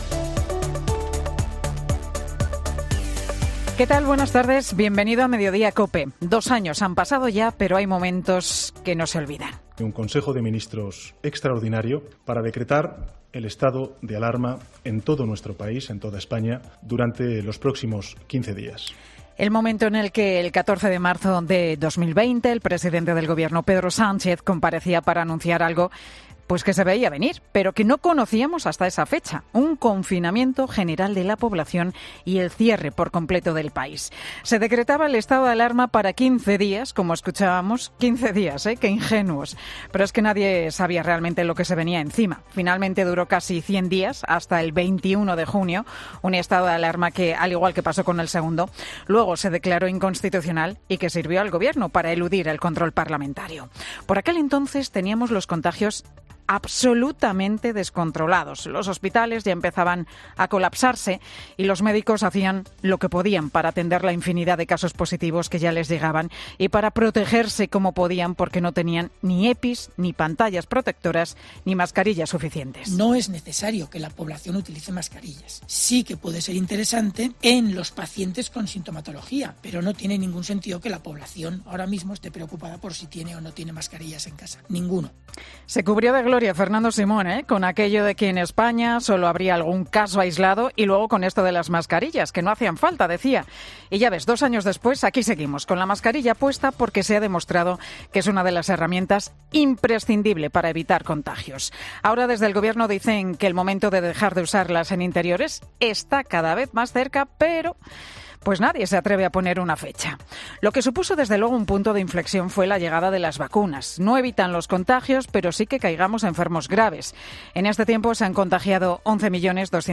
Monólogo de Pilar García Muñiz
El monólogo de Pilar García Muñiz, en Mediodía COPE